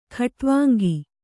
♪ khaṭvāŋgi